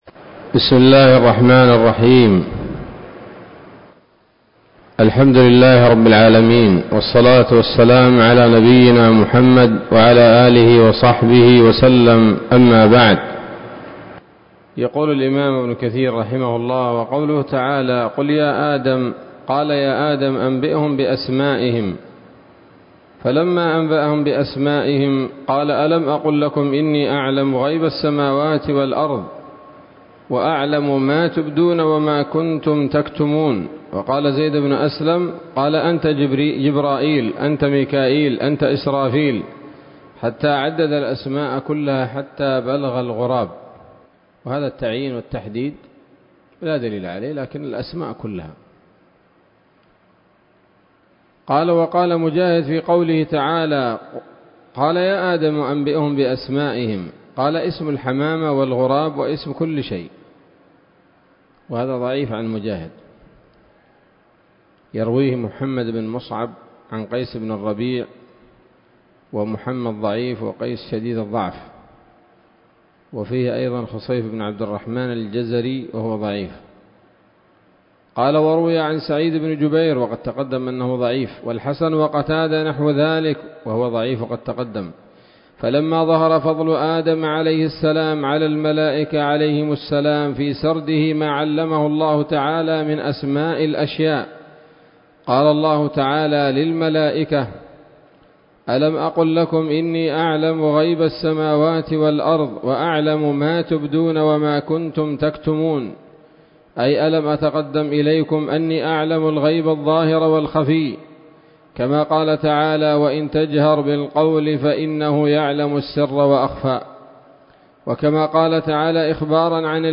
الدرس السابع والثلاثون من سورة البقرة من تفسير ابن كثير رحمه الله تعالى